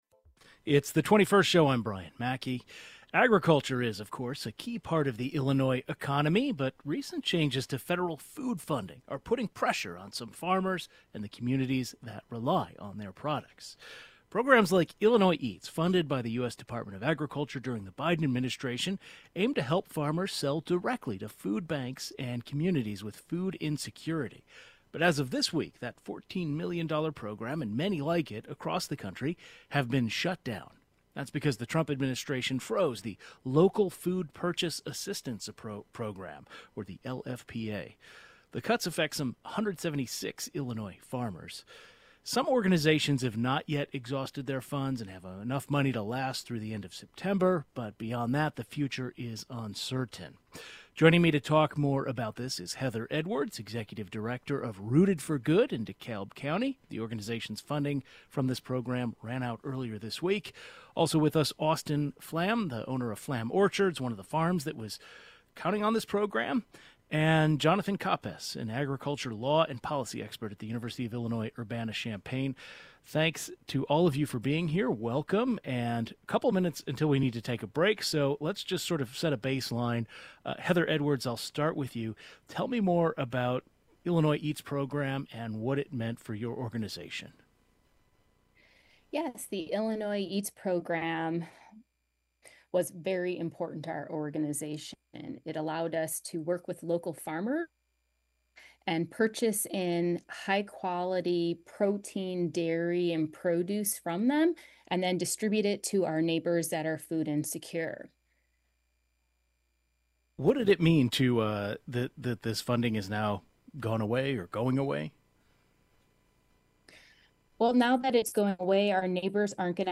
A farmer who was counting on this program, the head of an organization that also depends on the funds, and experts on agricultural law and policy join the program.